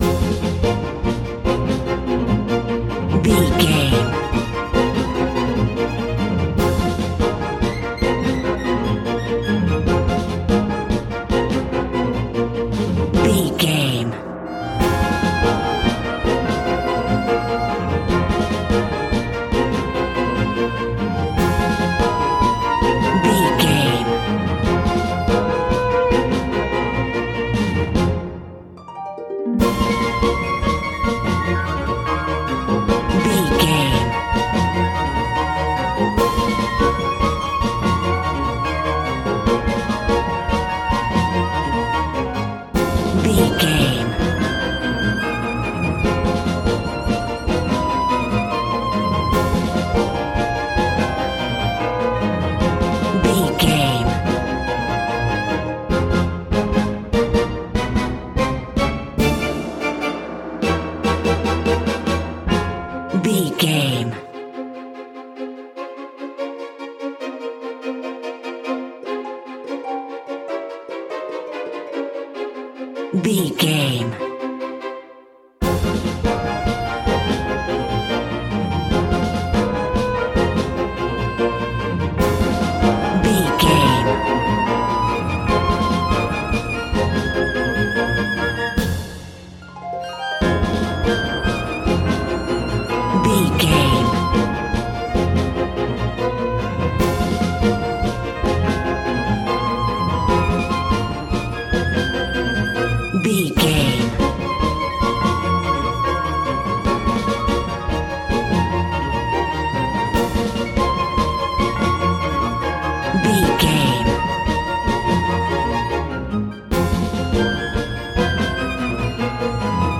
Ionian/Major
Fast
frantic
orchestra
violin
strings
brass section
cello
piccolo
flute
suspenseful
aggressive
drums
percussion
harp